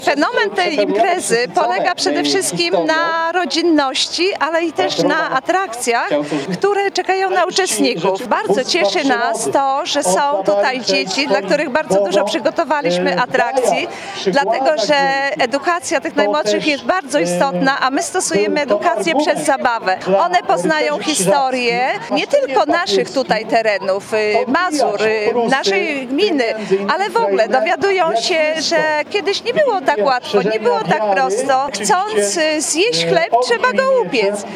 W czym tkwi fenomen tej imprezy historycznej, wyjaśnia gospodarz gminy.